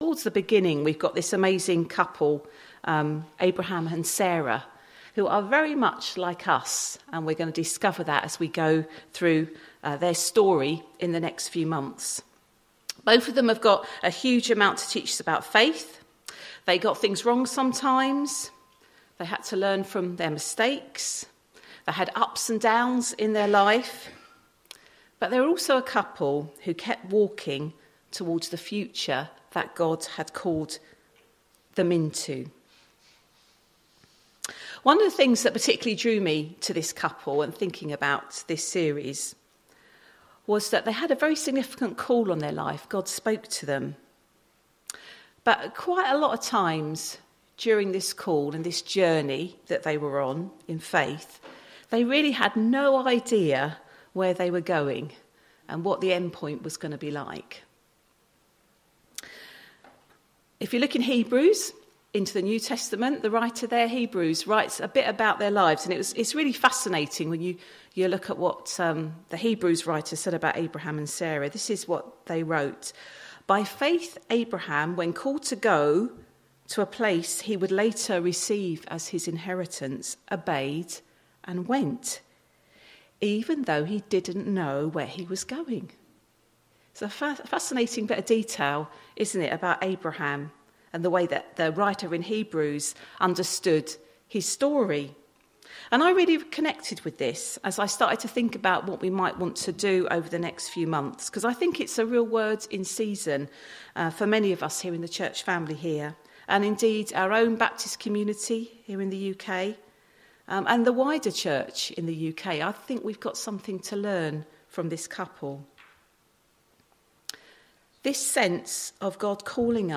Recording-02.02.2025-Sunday-Service.mp3